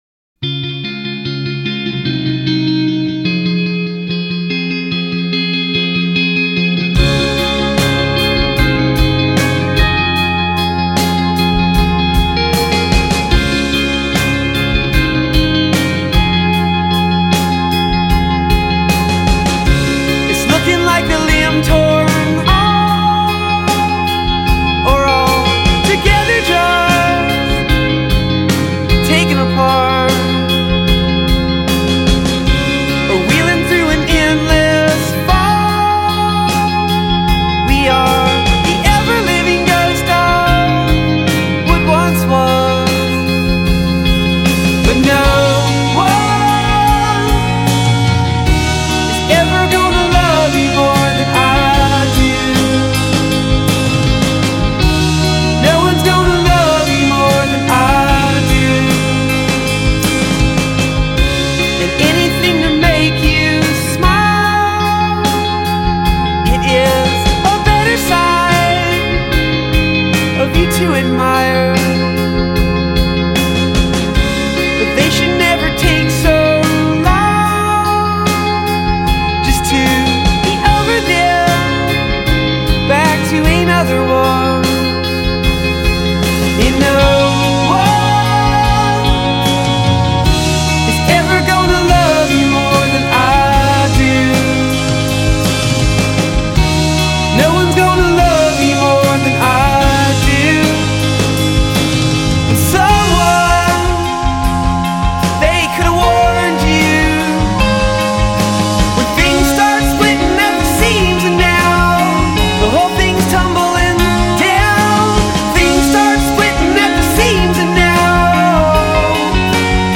A beautifully melodic track